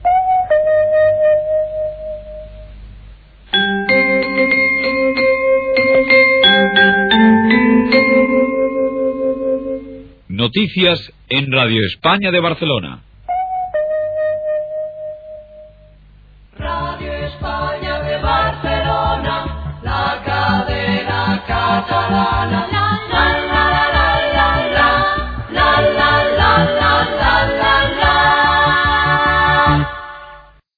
Toc de l'hora, identificació del programa, indicatiu cantat de l'emissora